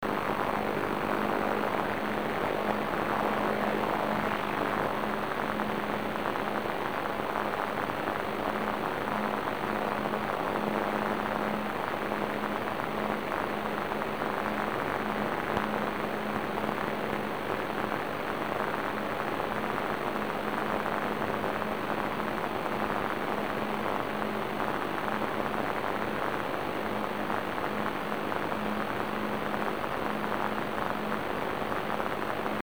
Сигнал подобен DCF77.
На пределе слышмости можно послушать сигнал, скачав
75kHz_HBG.mp3